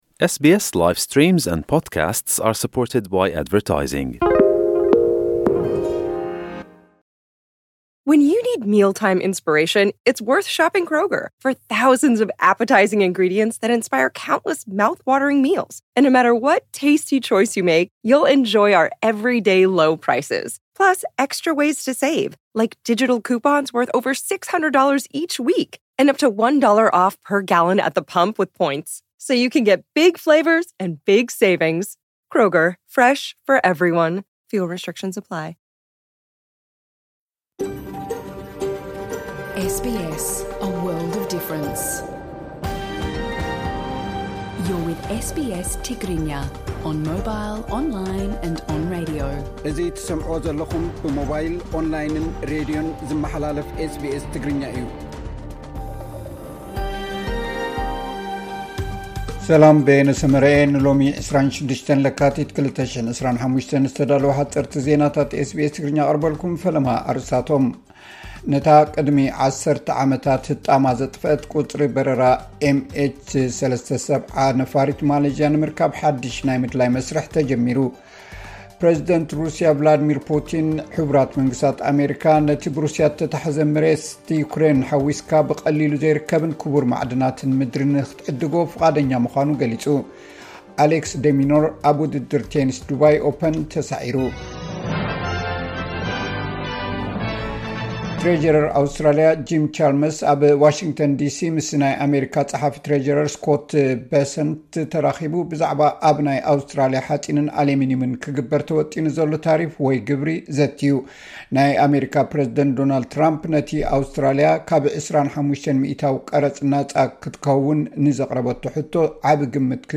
ዕለታዊ ዜና ኤስ ቢ ኤስ ትግርኛ (26 ለካቲት 2025)